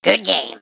goodgame